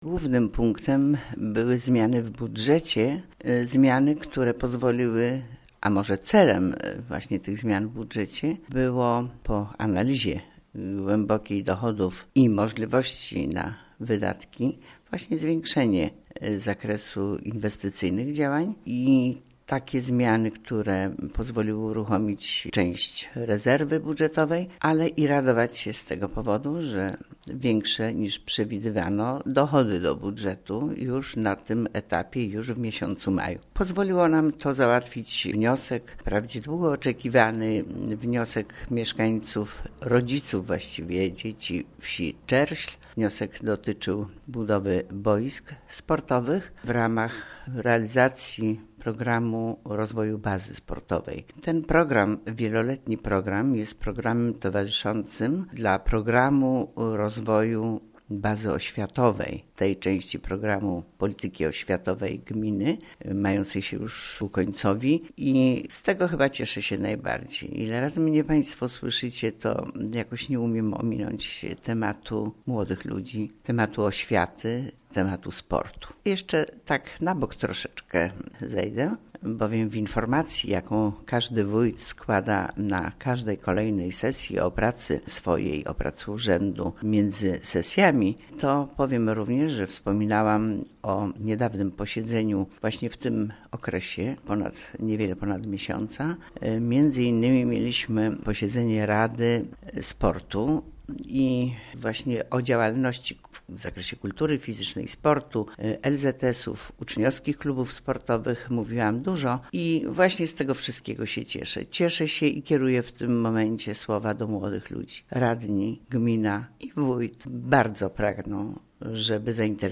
posłuchaj także komentarza Wójta do bieżącej sesji